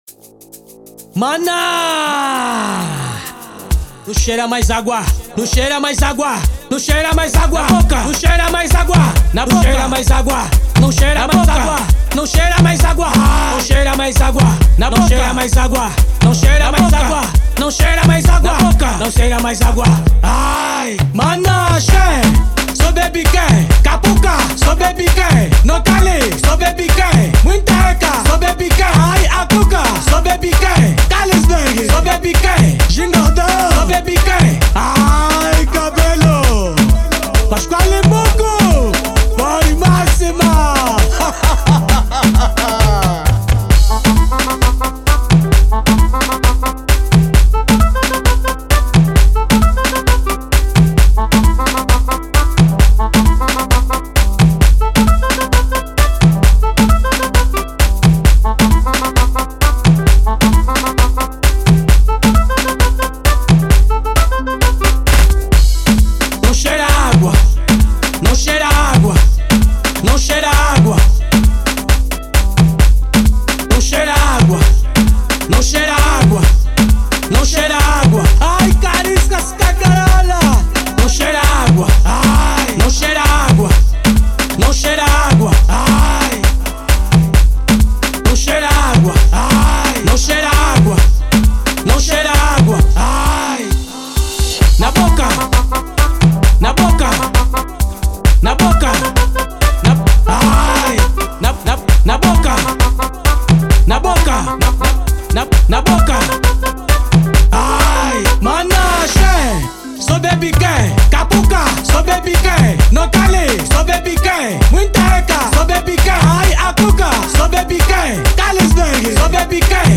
Gênero : Afro House